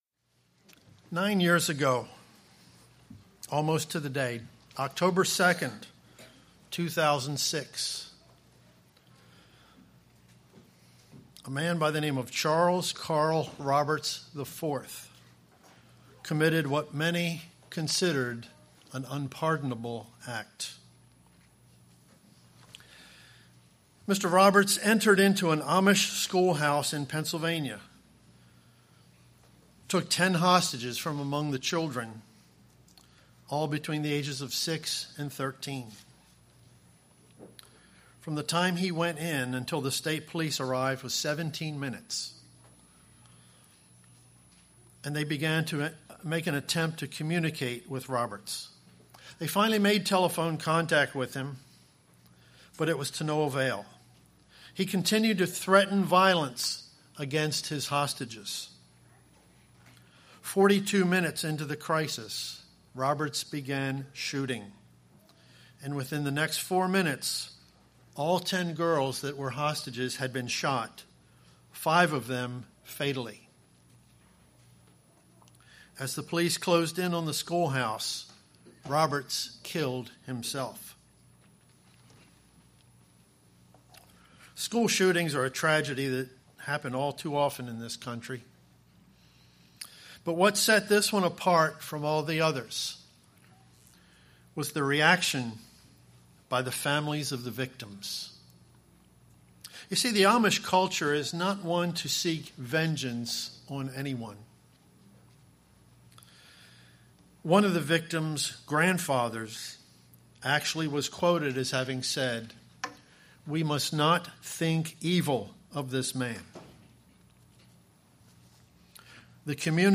This sermon was given at the Ocean City, Maryland 2015 Feast site.